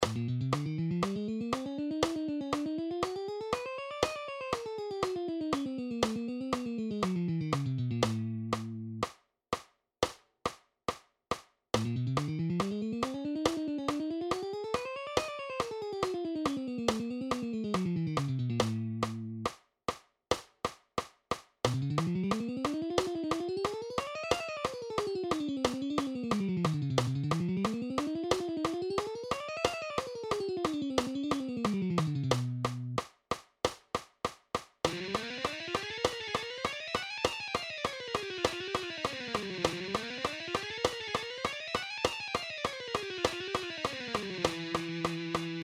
Download Guitar Tab For Guitar Speed Lesson / Speed Picking Technique
Its a great guitar warm up lesson.
John-Petrucci-Guitar-Speed-Lesson.mp3